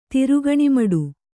♪ tirugaṇi maḍu